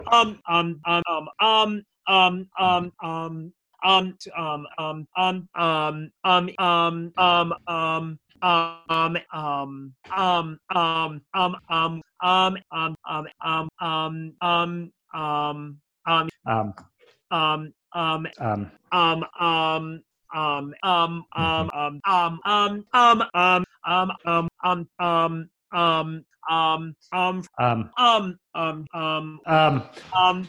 snipped out a bit of hemming and hawing from a recent episode
baritone
bass voices
Enjoy this enchanting, lyrical short song that’s easy to memorize!
ummm.mp3